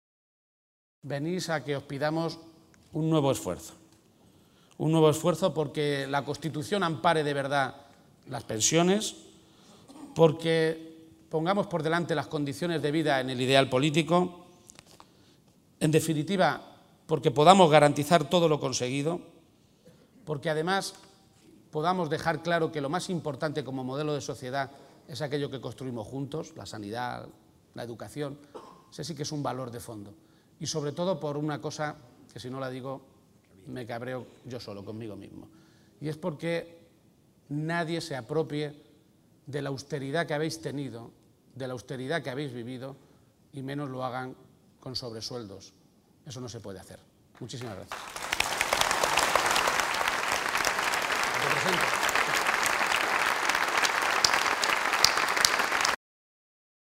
García-Page se pronunciaba de esta manera esta mañana, en Toledo, durante el primer gran acto a nivel nacional que el PSOE organiza en defensa del sistema de pensiones y en el que ha estado acompañado por el secretario general del PSOE, Alfredo Pérez Rubalcaba.